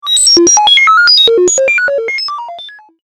ピコピコメカノイズ1長.mp3